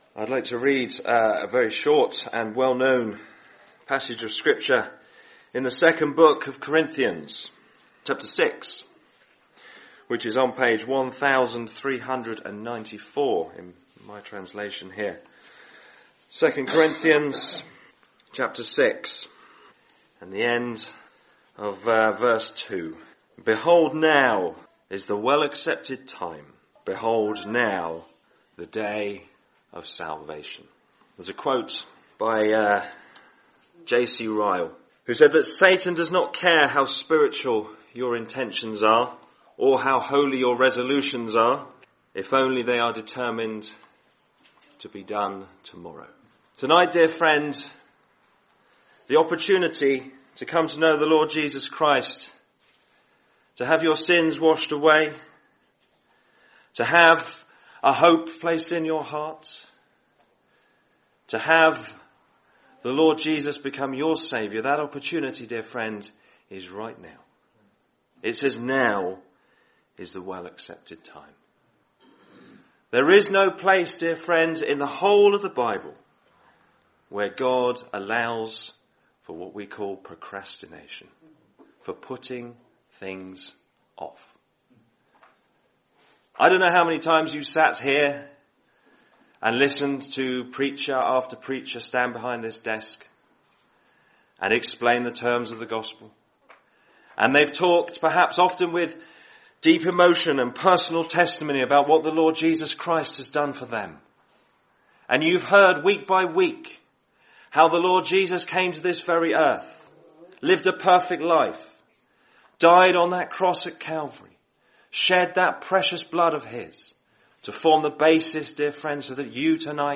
Gospel Preachings
Worthing